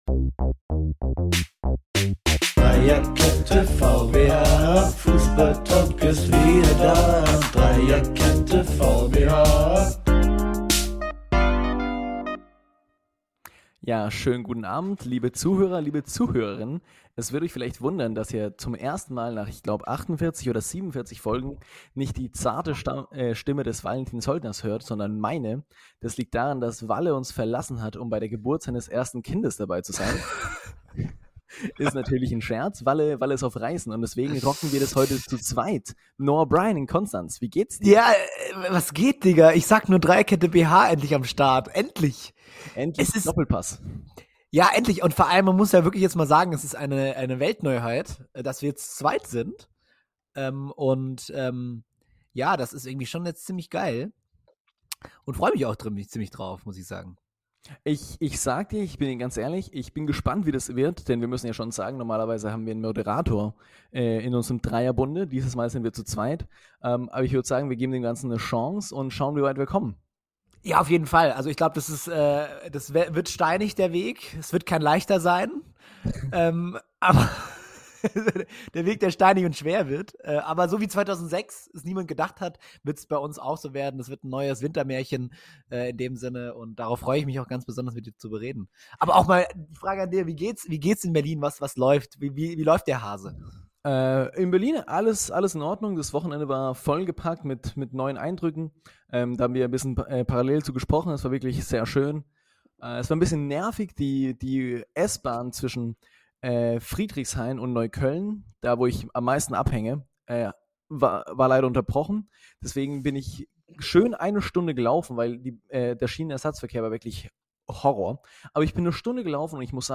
Keine Moderation, dafür viel DFB-Bashing, Kommentaren zum neuen Trump in Argentinien und die altgewohnten technischen Komplikationen.